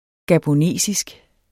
gabonesisk adjektiv Bøjning -, -e Udtale [ gaboˈneˀsisg ] Betydninger fra landet Gabon i det vestlige Centralafrika; vedr.